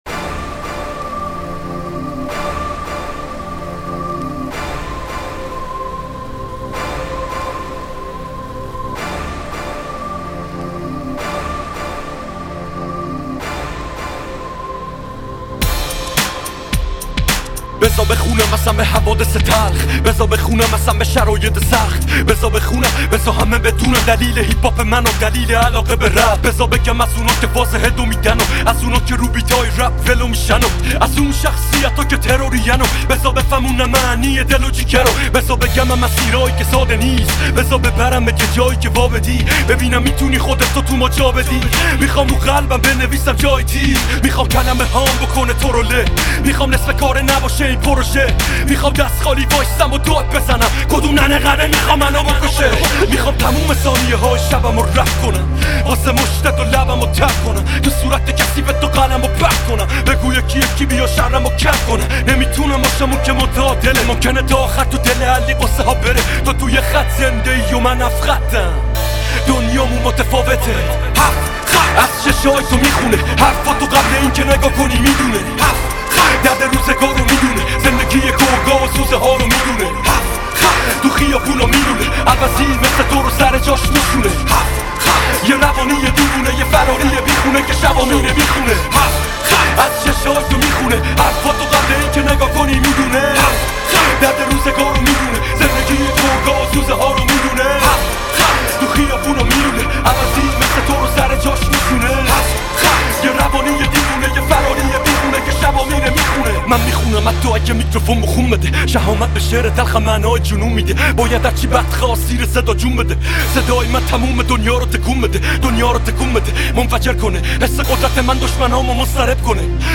آهنگ جدید عاشقانه و جذاب